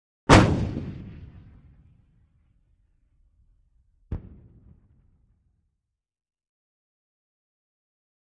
Howitzer Artillery Cannon: Fire; Sharp Metallic Sounding Missile Launch With Short Echo Followed By Distant Explosion When Missile Hits Its Target. Good Outgoing Missile. Launch Is Very Close Up.